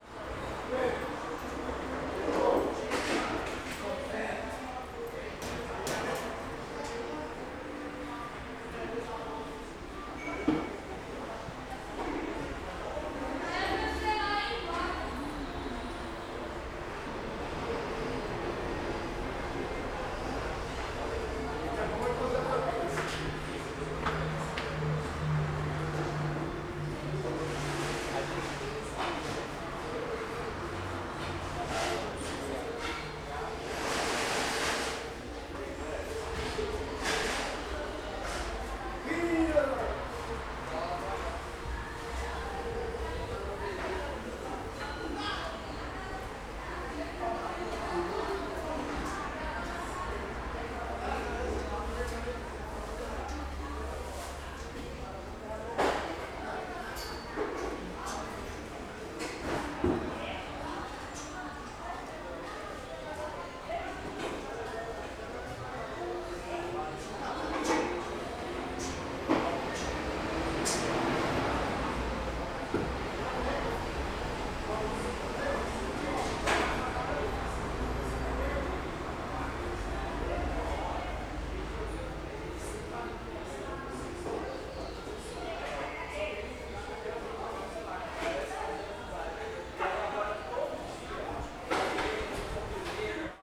CSC-11-002-GV - Feira do Paranoa com Feirantes abrindo e conversando alto.wav